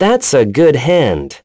Esker-Vox_Skill5.wav